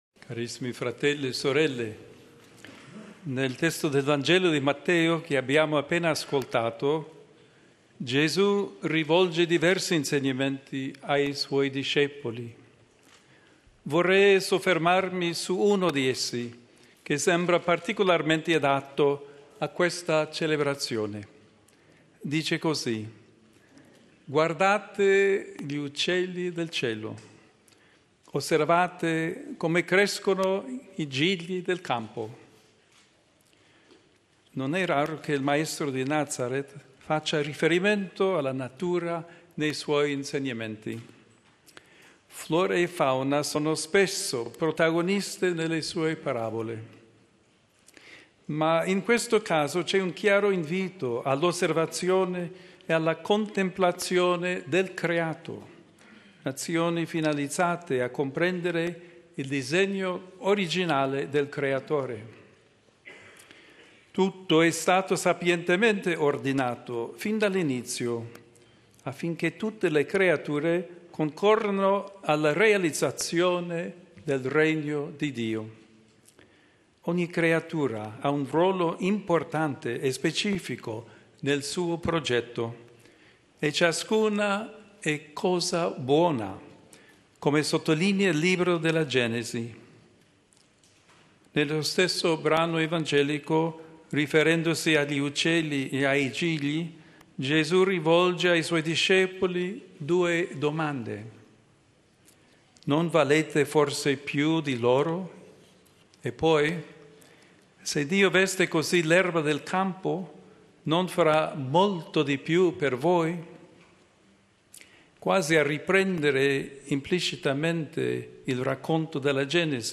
Omelia del Papa Leone XIV nella Santa Messa per l'inaugurazione del “Borgo Laudato si’” (Ville Pontificie di Castel Gandolfo, 5 settembre 2025)